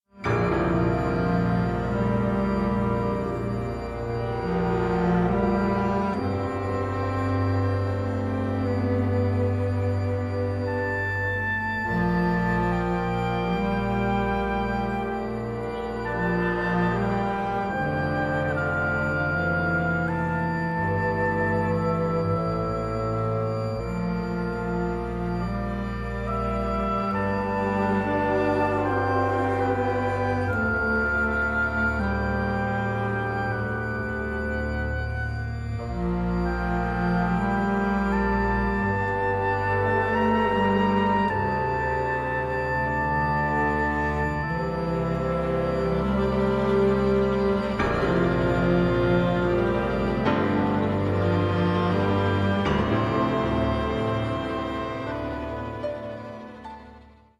24 bit digital recording
soprano
flute
oboe
clarinet
bassoon
horn
violin
viola
cello
bass
piano
electric violin
percussion